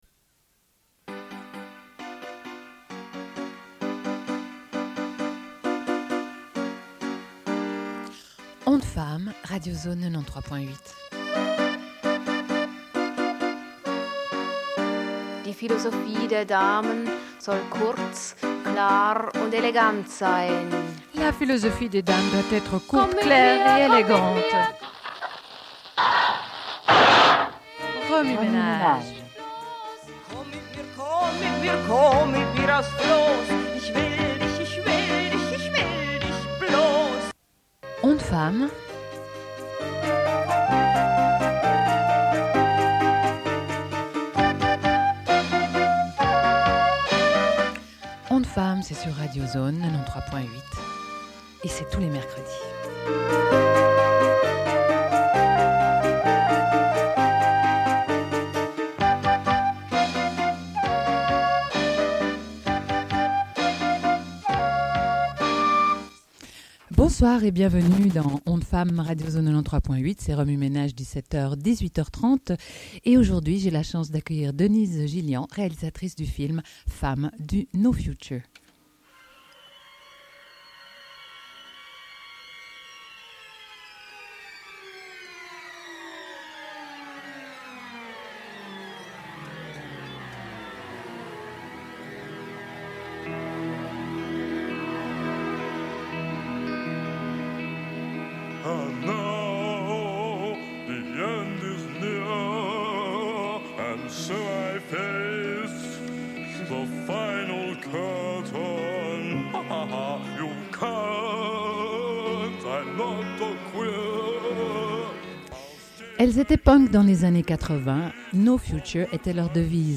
Une cassette audio, face A
Radio